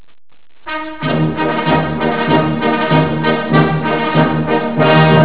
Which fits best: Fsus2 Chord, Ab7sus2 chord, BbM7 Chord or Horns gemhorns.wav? Horns gemhorns.wav